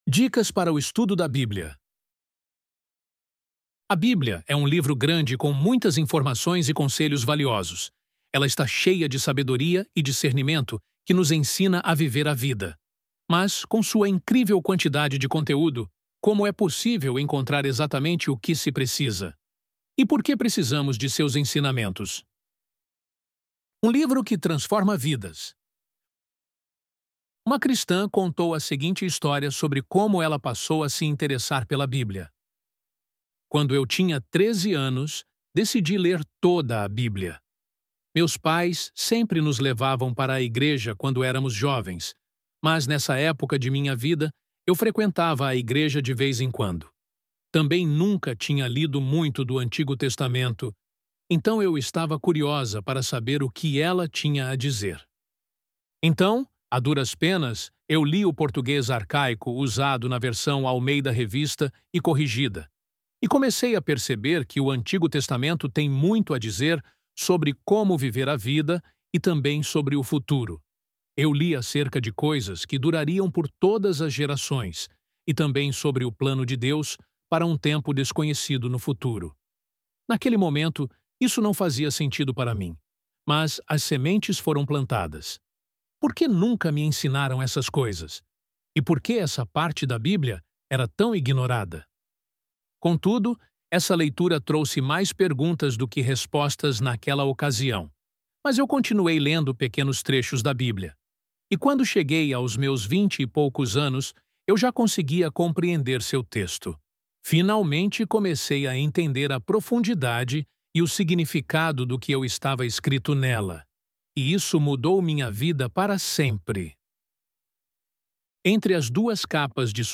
ElevenLabs_Dicas_Para_o_Estudo_da_Bíblia.mp3